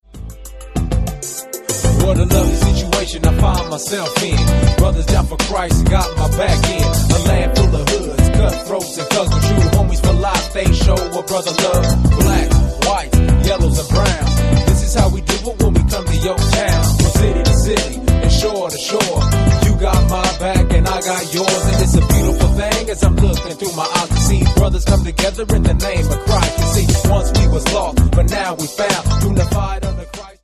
Das West Coast Trio
• Sachgebiet: Rap & HipHop